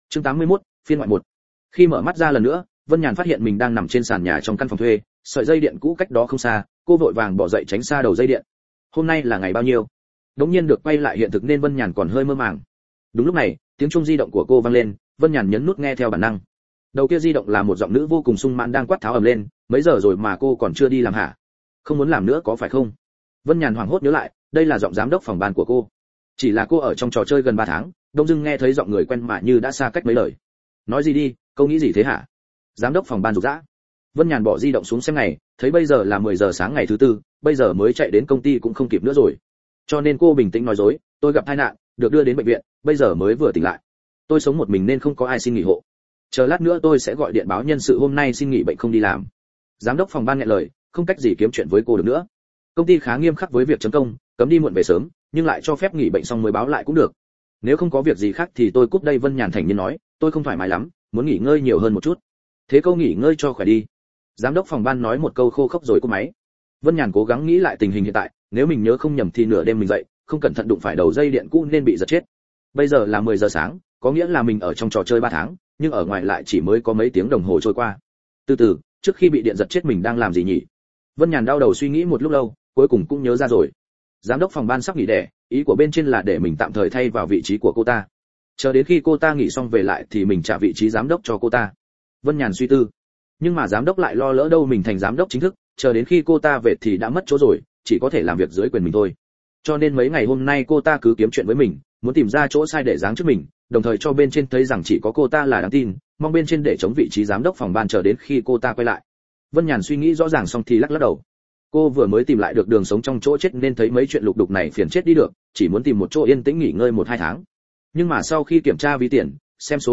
Vô Hạn Tháp Phòng Audio - Nghe đọc Truyện Audio Online Hay Trên AUDIO TRUYỆN FULL